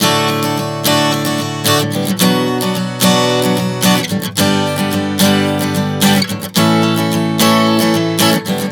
Prog 110 D-Bm-G-A.wav